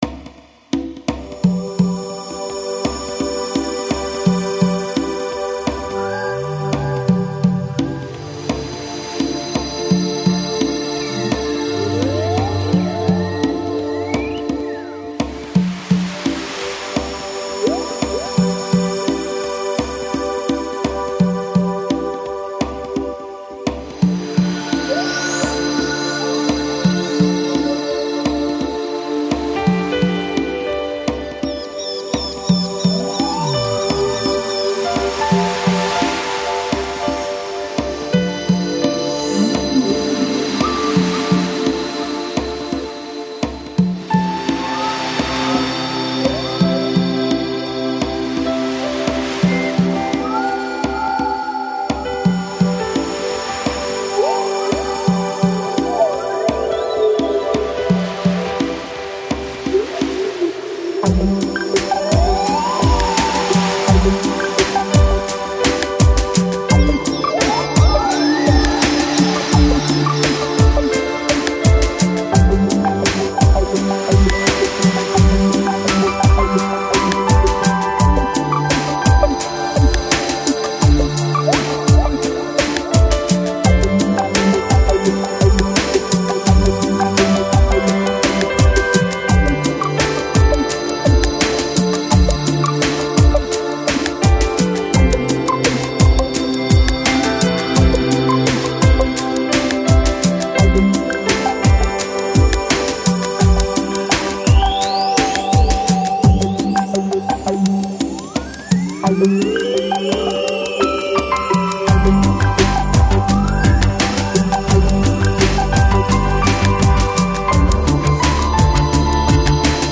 Жанр: Electronic, Chill Out, Lounge, Downtempo